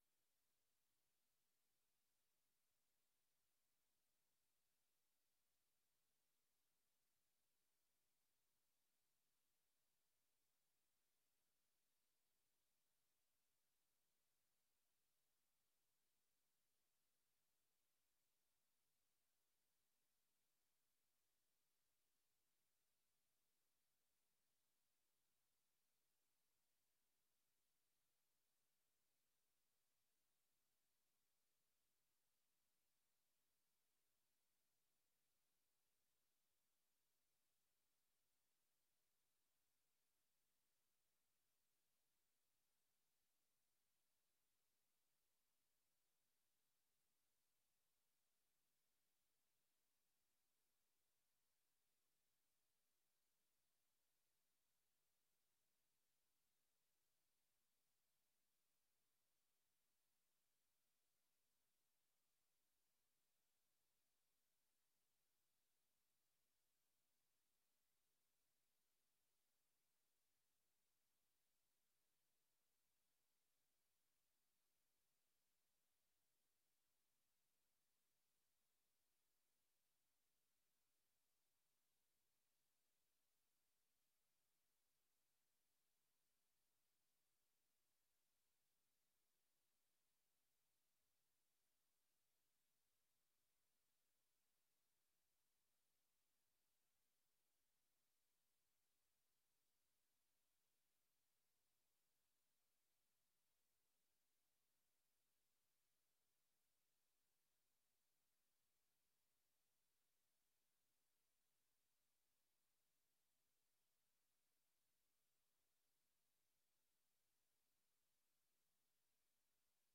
Locatie: Voorrondezaal Lingewaal